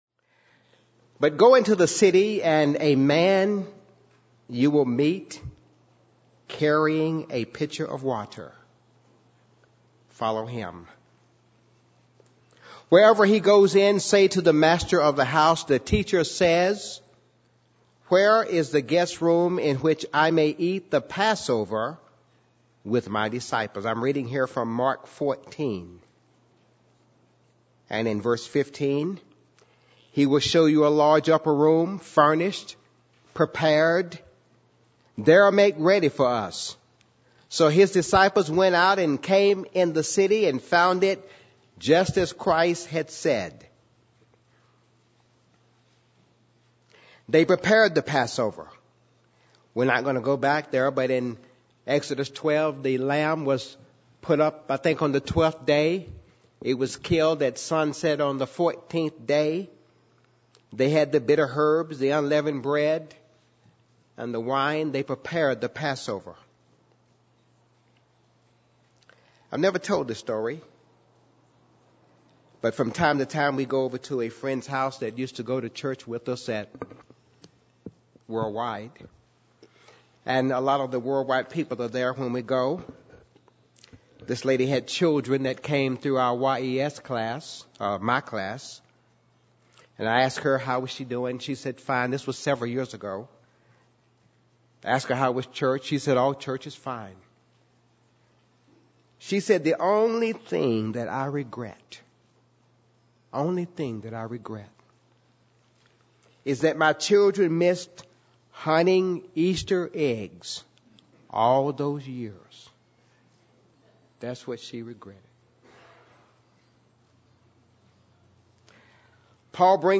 This sermon reviews several groups of scriptures, in both Old and New Testaments, that describe the enormity of what Jesus Christ did for all mankind.